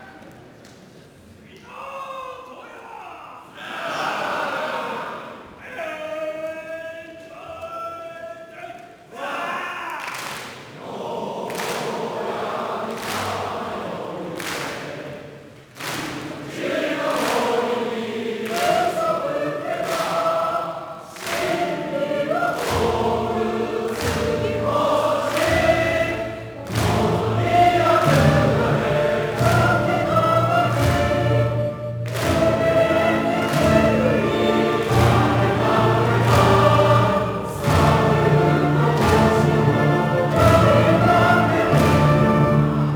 つづけて、金大オーケストラ。
可能でしたら、低音を強くしてお聴き頂きますと、四高の「超然」太鼓の音も聞こえてきます。